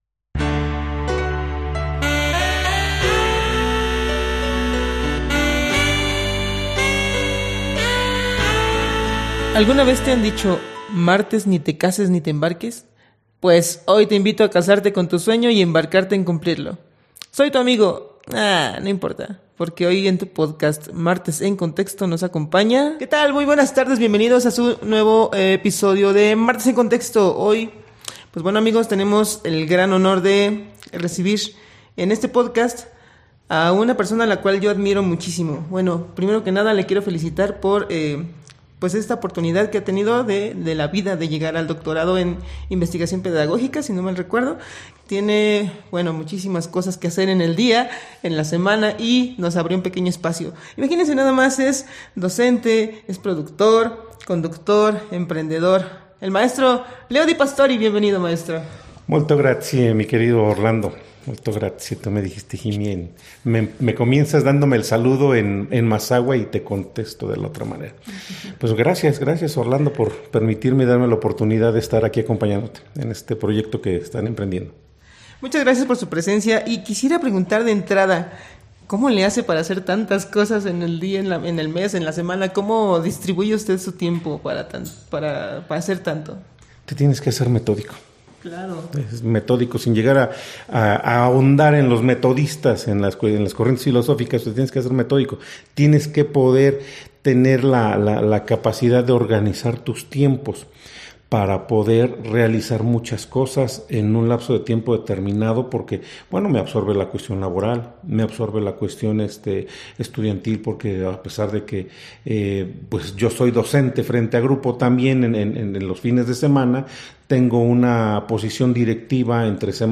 Locación: NEAR OFFICE.